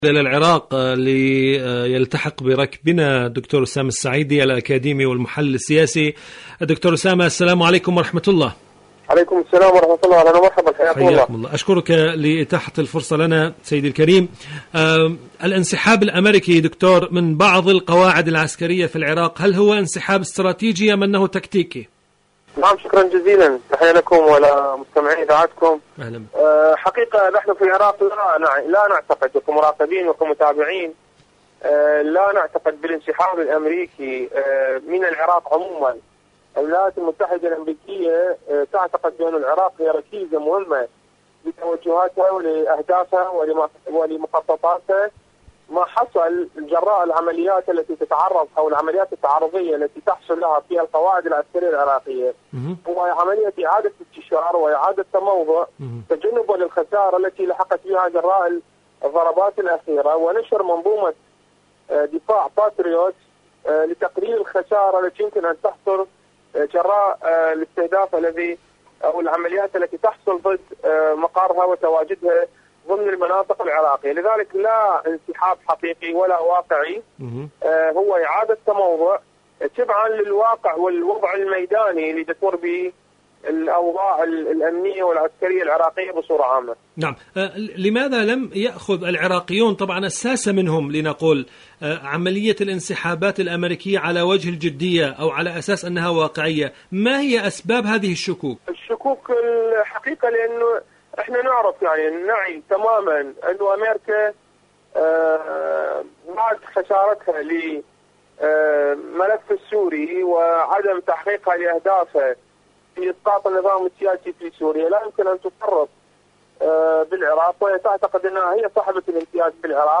إذاعة طهران-حدث وحوار: مقابلة إذاعية